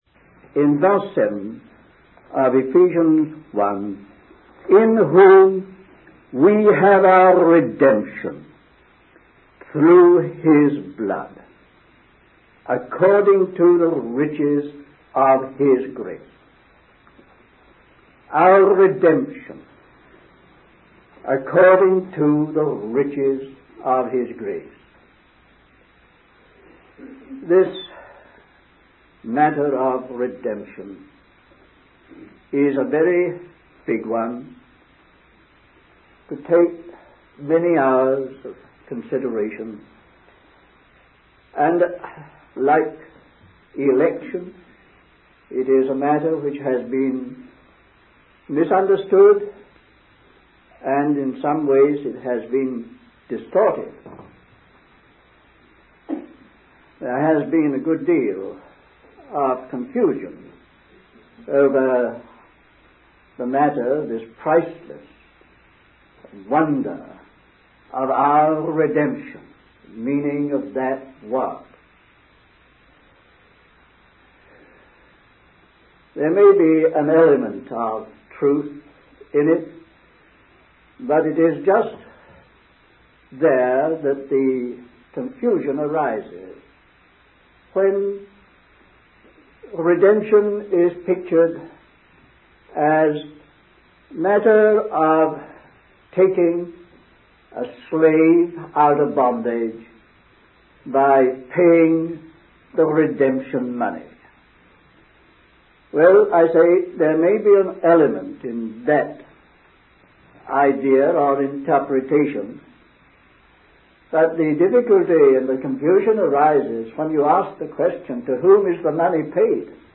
In this sermon, the speaker discusses the concept of balance and completeness in relation to God's possession and redemption.